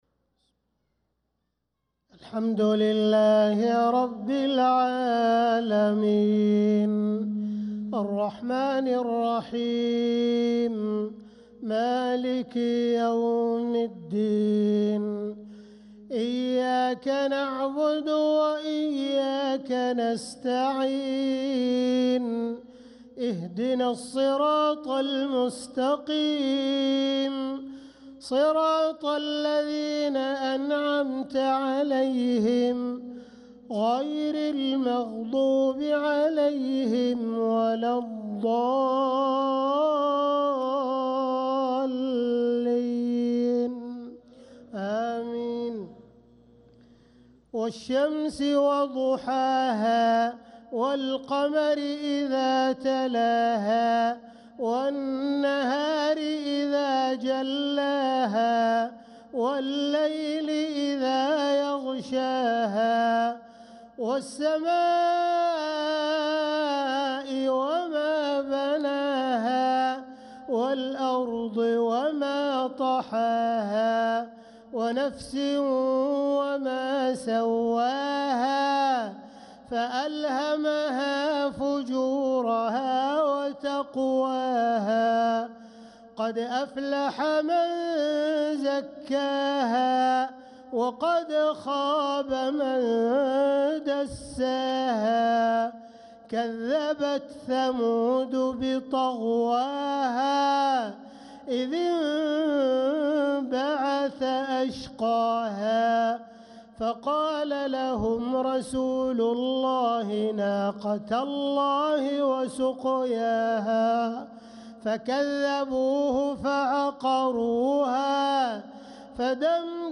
صلاة العشاء للقارئ عبدالرحمن السديس 24 ربيع الآخر 1446 هـ